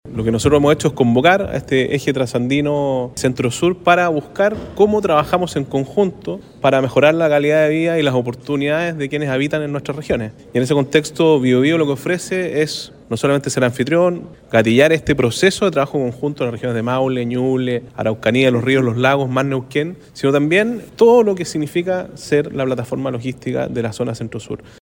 Fue el Salón Mural del Gobierno Regional el que albergó la reunión.